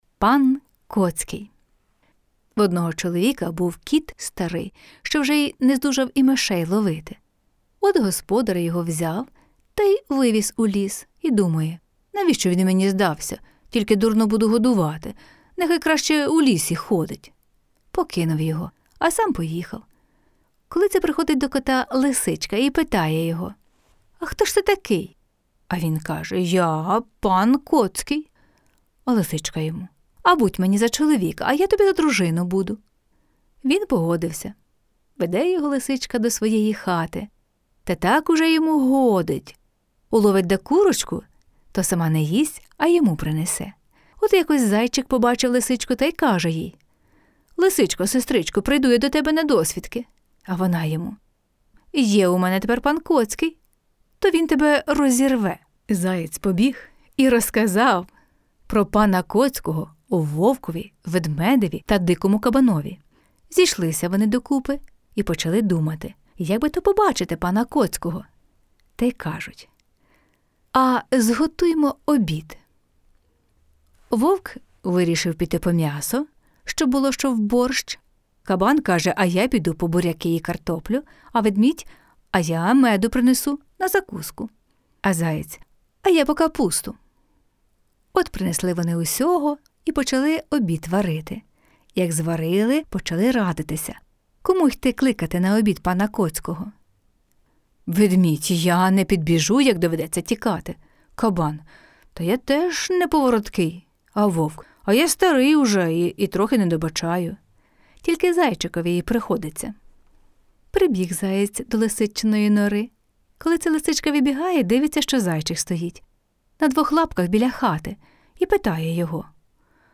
Жанр книги: Казка на добраніч.